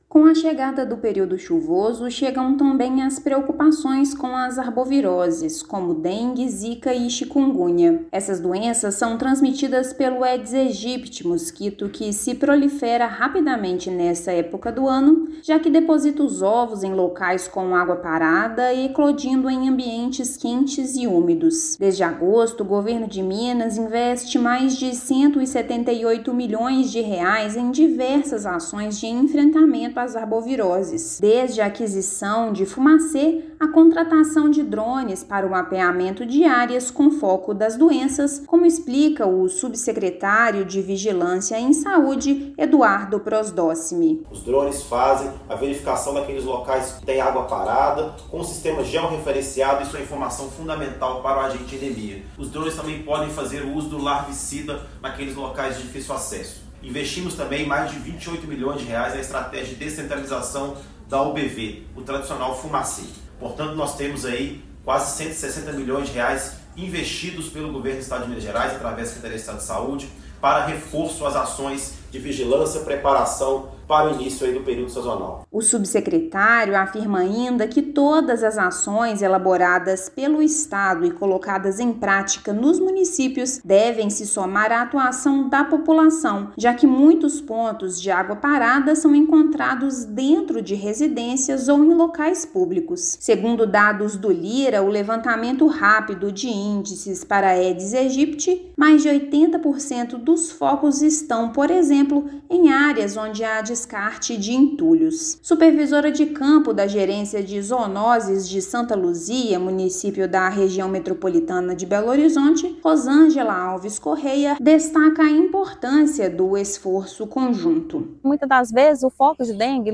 Secretaria de Saúde alerta que combate ao Aedes aegypti deve ser feito regularmente e que o papel de cada cidadão é crucial para eliminar focos dentro e fora de casa. Ouça matéria de rádio.